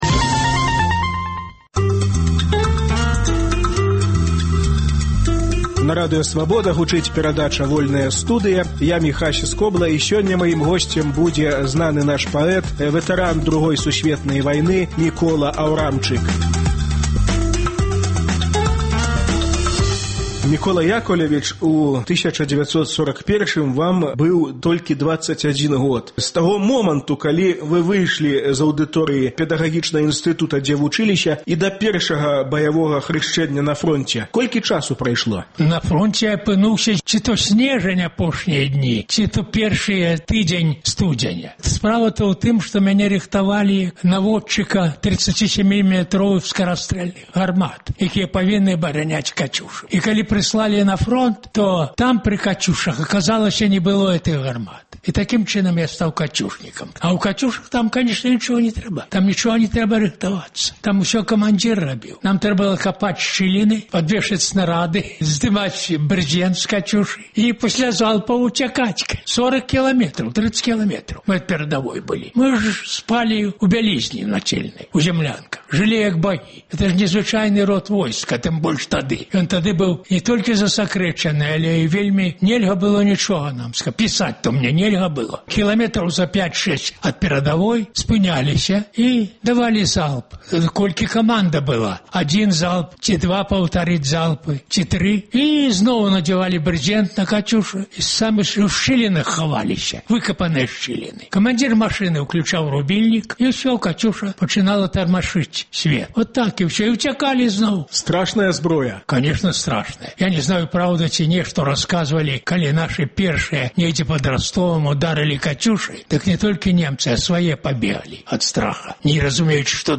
Вайна вачыма палоннага – гутарка